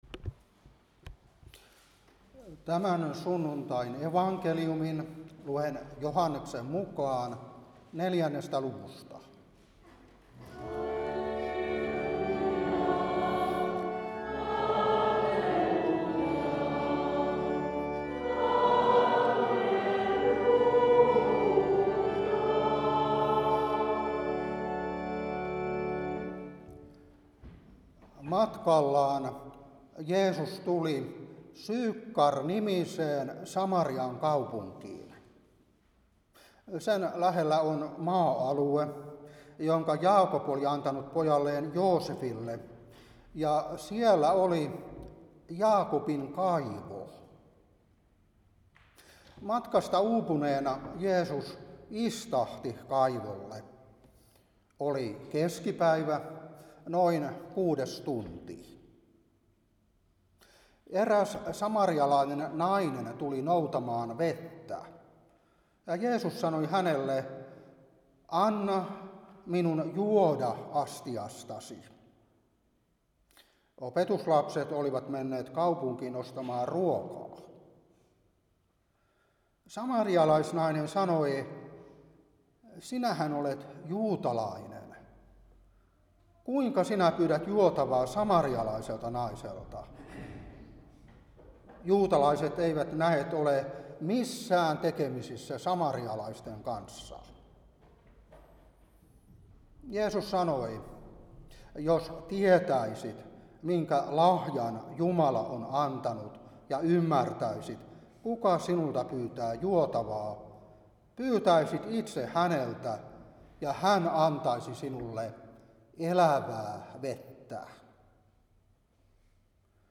Saarna 2026-1.